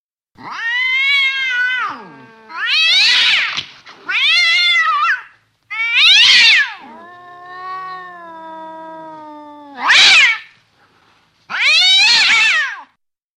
На этой странице собраны звуки кошачьих драк – от яростного шипения до громких воплей и топота лап.
Звук кошачьей ссоры в доме